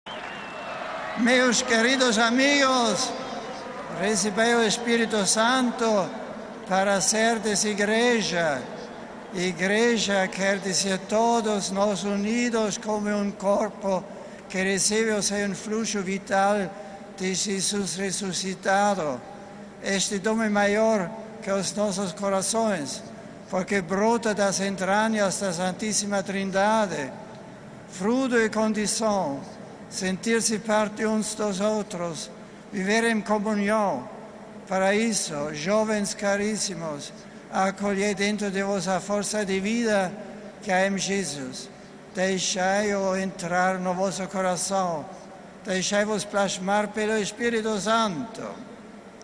Esta vigília de oração do Papa com os jovens neste sábado, no hipódromo de Randwick concluiu-se com a adoração Eucarística, e as saudações de Bento XVI em varias línguas
Esta a sua saudação aos jovens de língua portuguesa: RealAudio